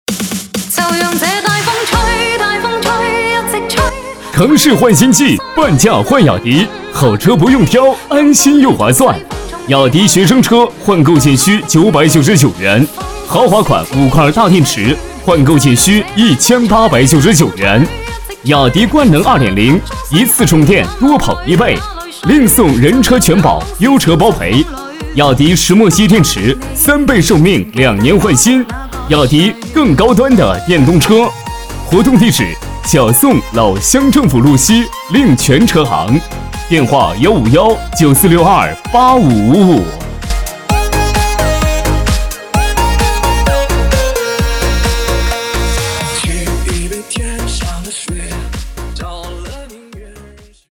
B类男10
【男10号促销】雅迪电动车
【男10号促销】雅迪电动车.mp3